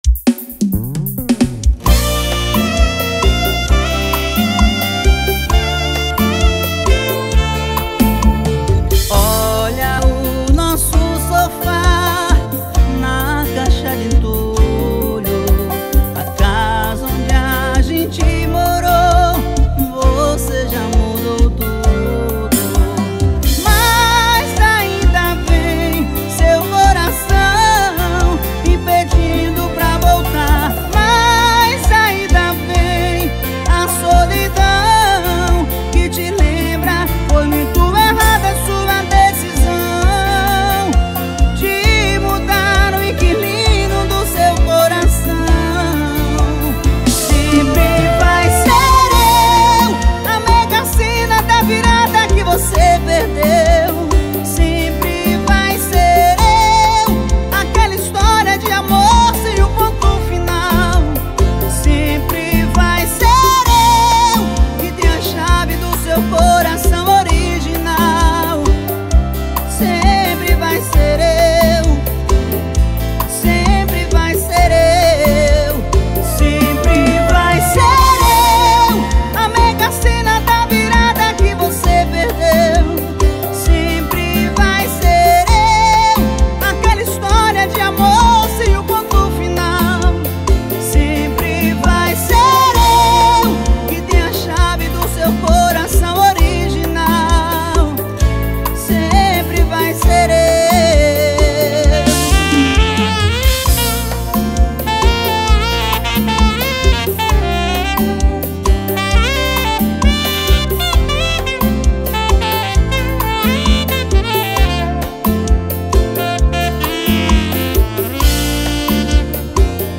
2024-07-04 20:45:52 Gênero: Forró Views